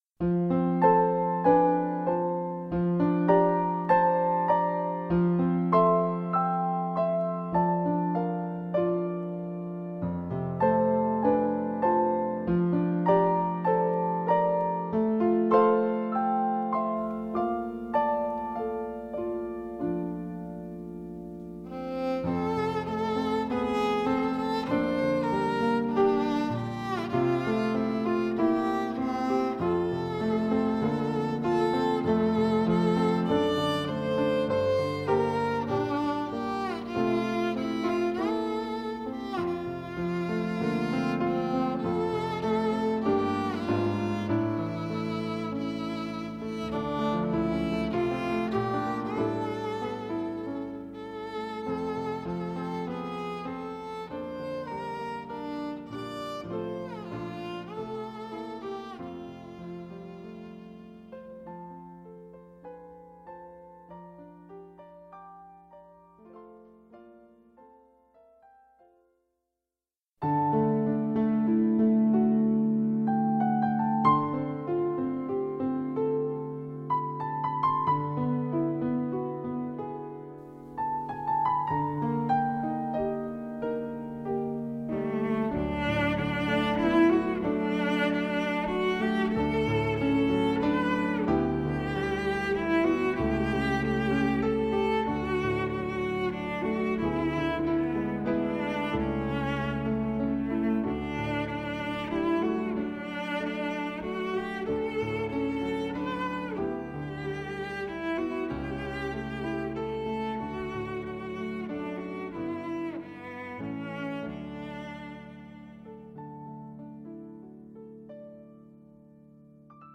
beautiful, acoustic renditions of
beloved Christmas carols--including